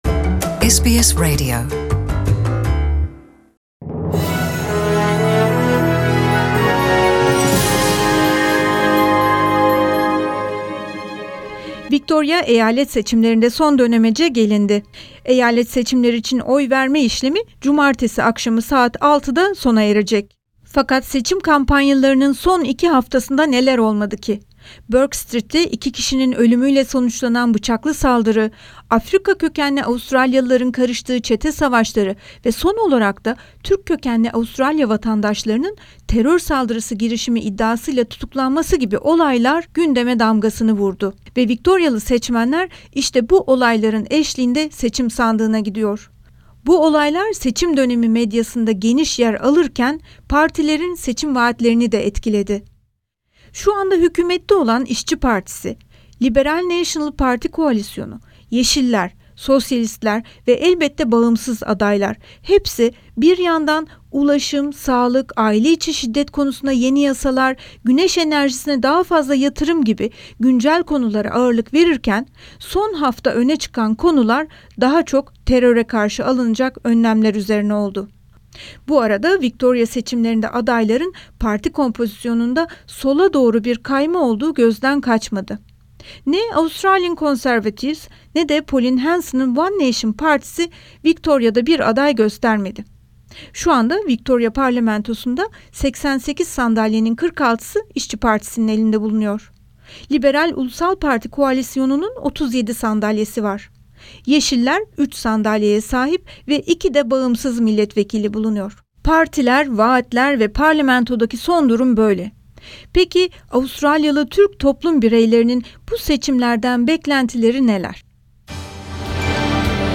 Victoria Eyalet Seçimleri'ne bir gün kala mikrofonumuzu toplum bireylerimize tuttuk. Bir vatandaş olarak ulaşımdan şehirciliğe, politikacılardan beklentilerini ve seçim sonuçlarına ilişkin öngörülerini bakın nasıl anlattılar...